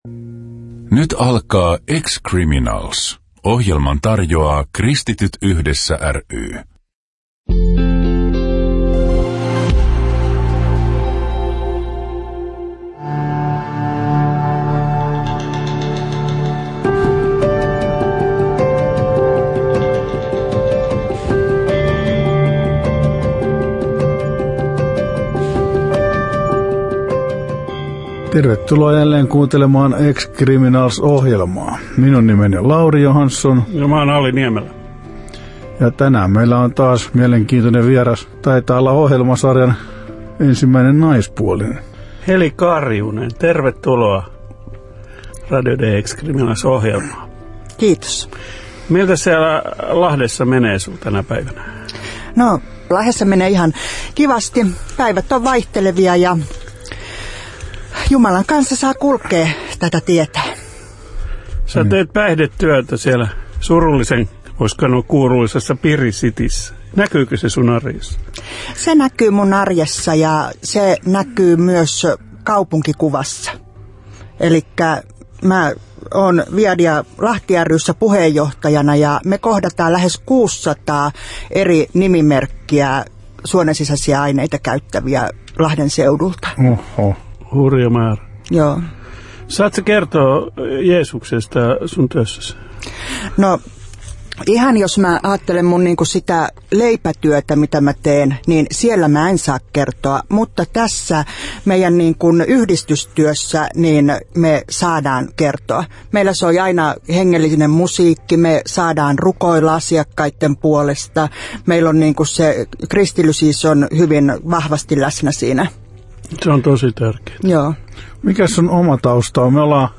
Musiikkina kuullaan vankien usein toivomaa kappaletta.